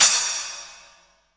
Index of /90_sSampleCDs/AKAI S6000 CD-ROM - Volume 3/Crash_Cymbal2/CHINA&SPLASH
SPLASH.WAV